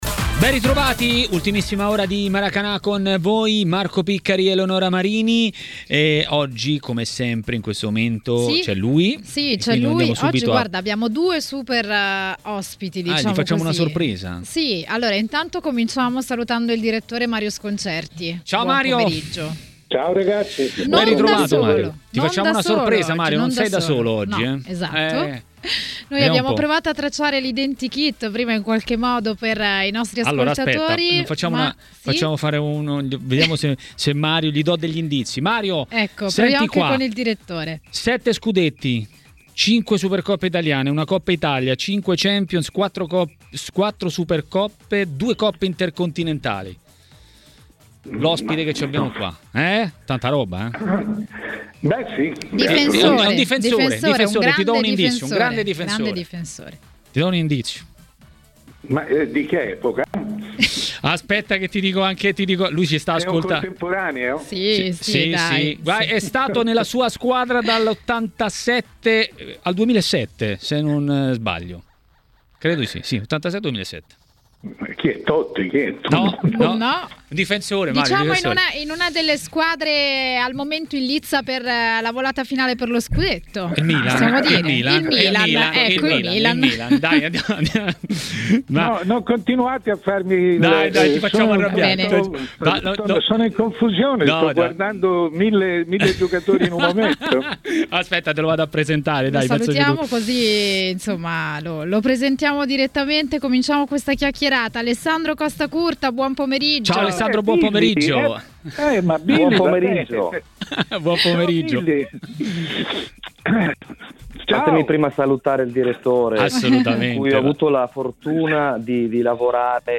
L'ex calciatore Alessandro Costacurta a Maracanà, nel pomeriggio di TMW Radio, ha parlato dei temi del giorno.